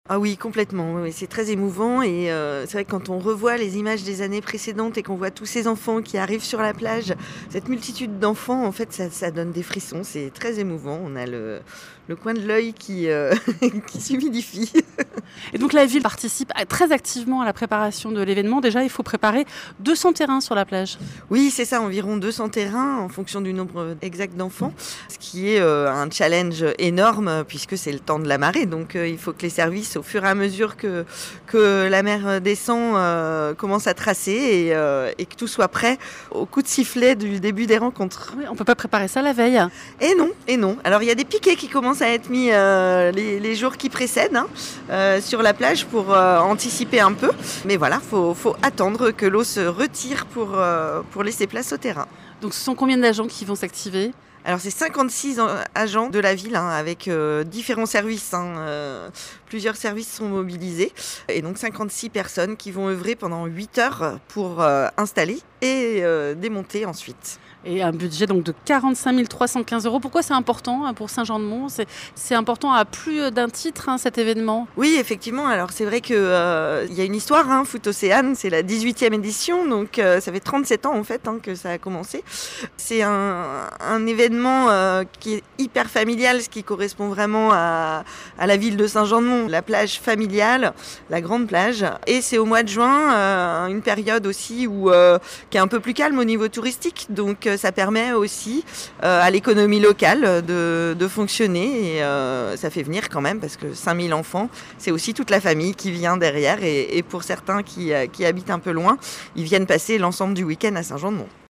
Podcast reportages